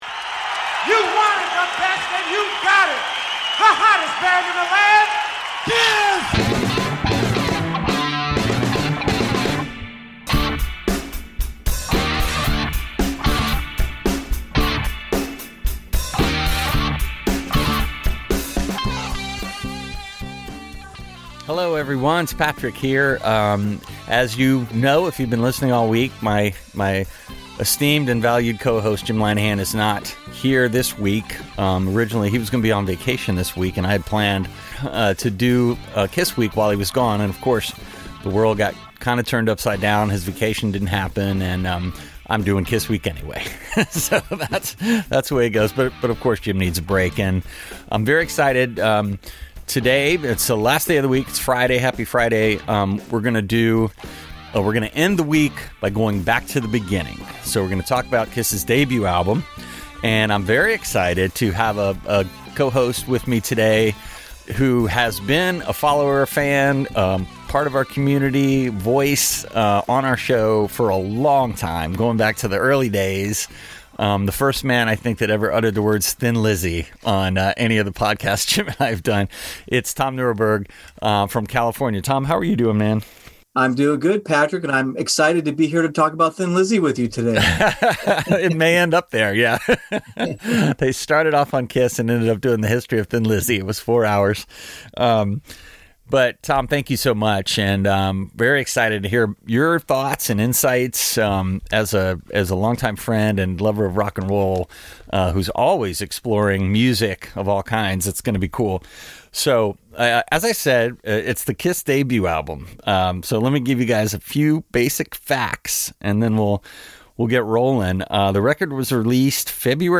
Kiss Week concludes with a discussion of the band's 1974 self-titled debut.